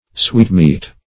Sweetmeat \Sweet"meat`\, n.